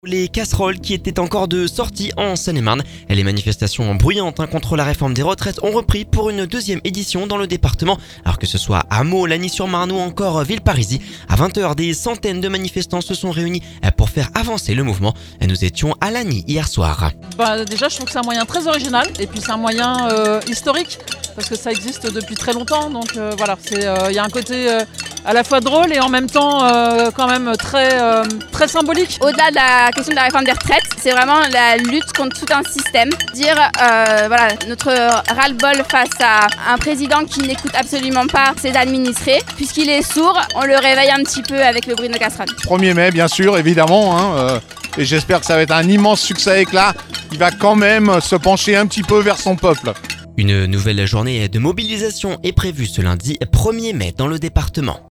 Les manifestations bruyantes contre la réforme des retraites ont repris pour une deuxième édition dans le département à Meaux, Lagny-sur-Marne et Villeparisis. A 20h, des centaines de manifestants se sont réunis faire avancer le mouvement. Nous étions à Lagny hier soir…